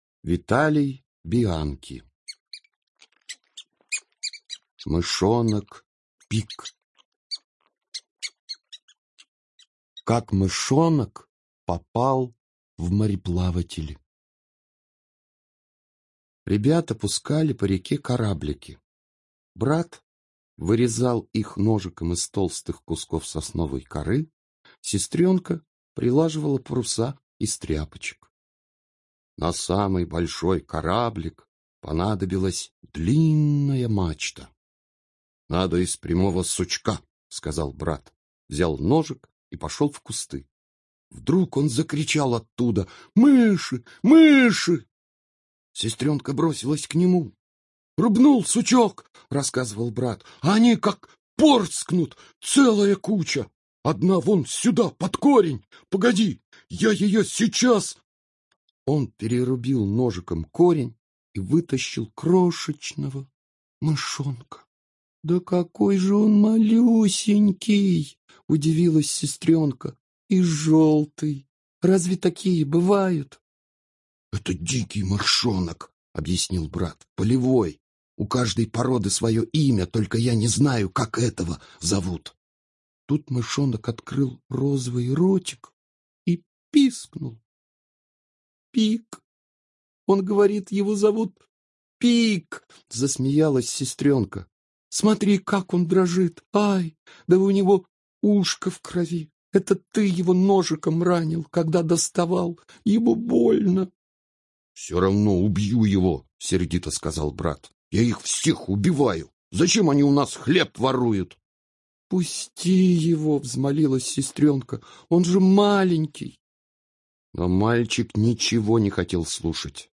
Мышонок Пик - аудиосказка Бианки В.В. Слушать онлайн. Брат с сестрой посадили маленького мышонка на кораблик, сделанный ими из сосновой коры...